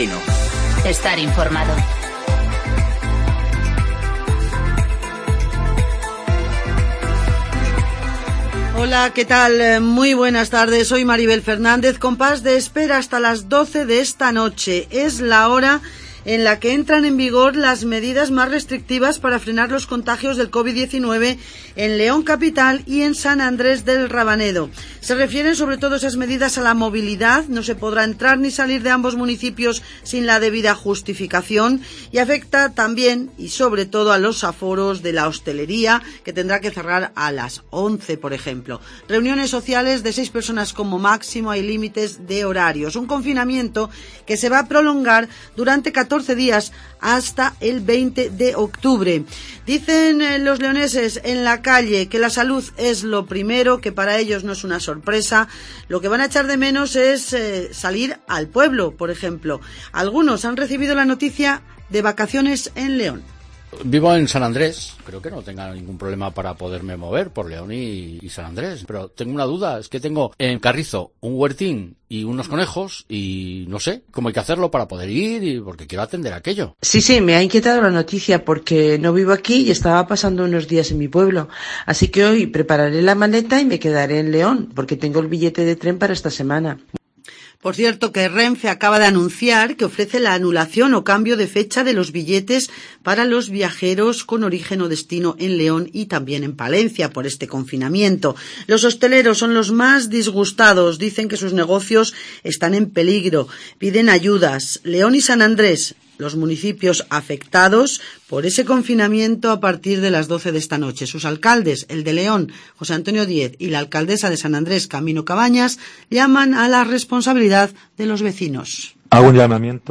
INFORMATIVOS
Conocemos las noticias de las últimas horas del Bierzo y León, con las voces de los protagonistas.